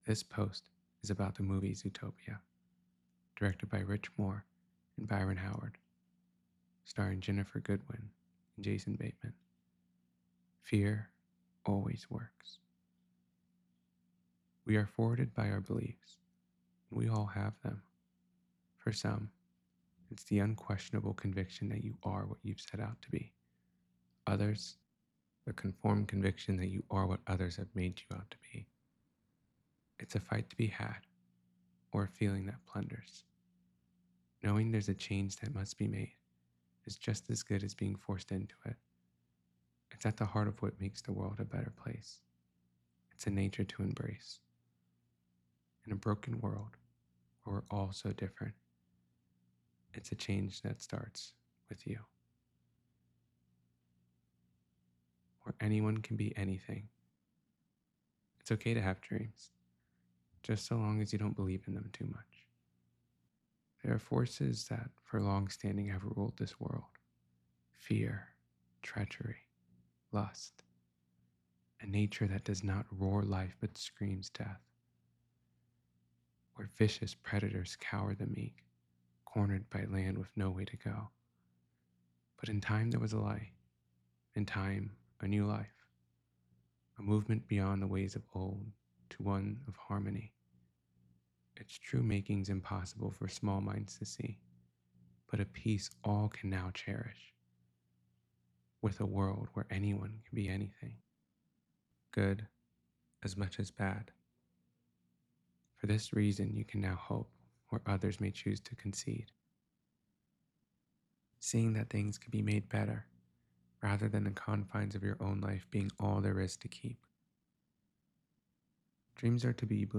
zootopia-to-know-a-story-reading.mp3